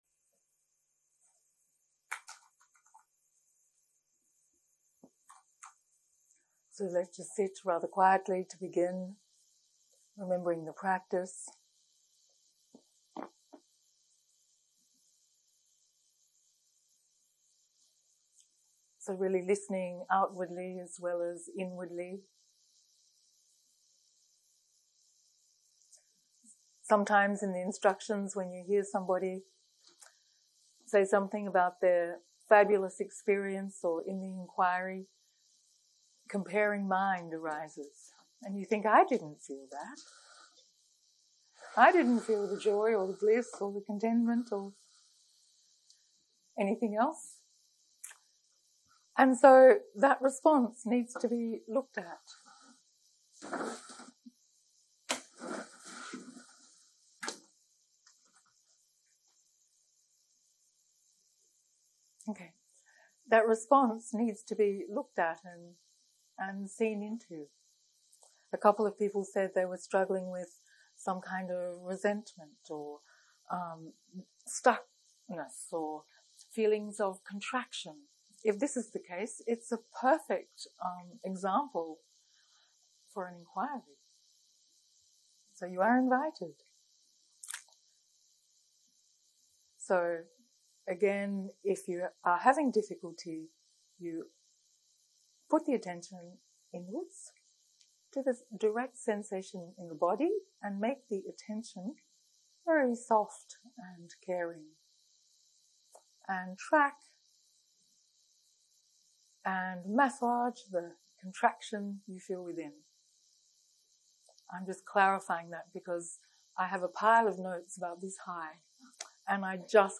הקלטה 15 - יום 6 - צהרים - חקירה
סוג ההקלטה: חקירה